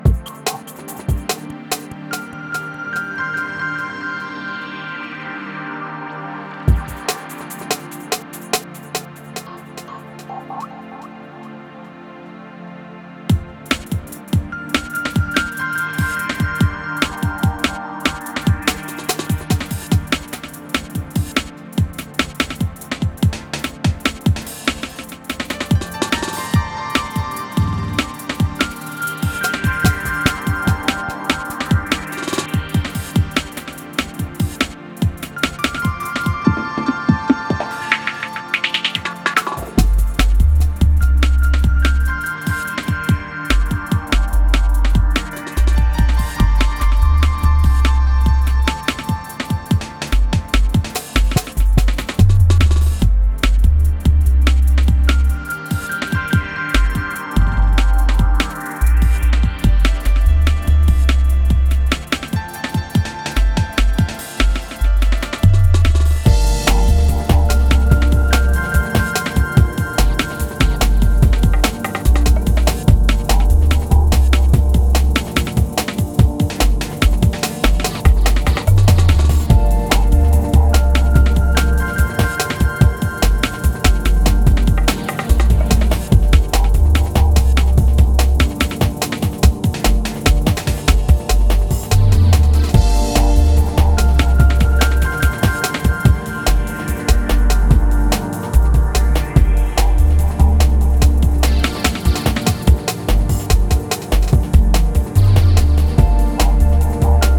Neo Goa / Progressive Trance tracks
aery Drum’n’bass experimentations